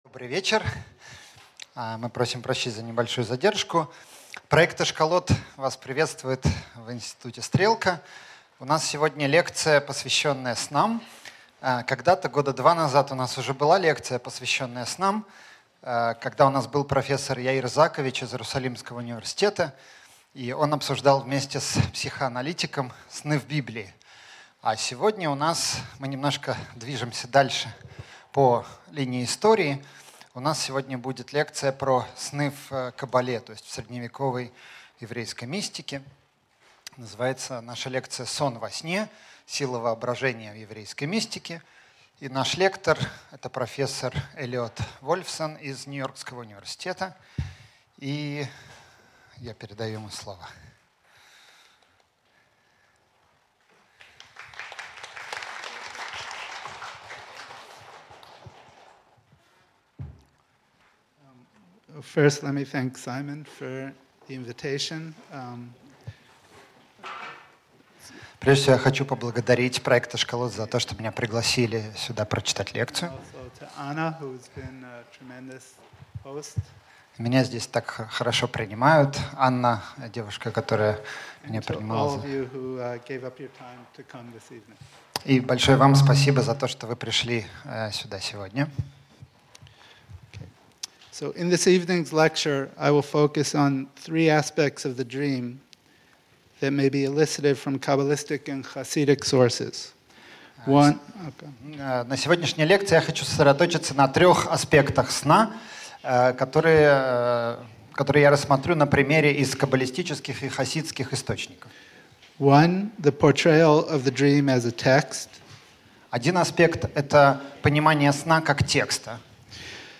Аудиокнига Сон во сне: сила воображения в еврейской мистике | Библиотека аудиокниг